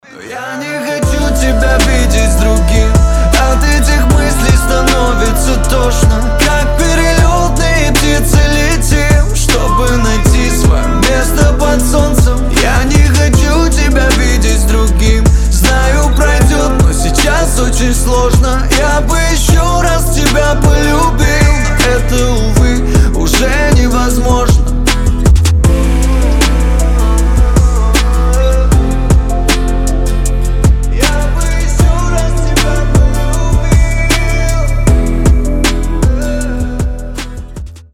• Качество: 320, Stereo
лирика
грустные